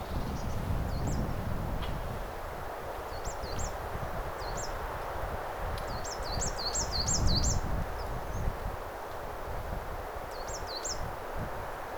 kuusitiaisen laulua
vahan_kuusitiaisen_laulua.mp3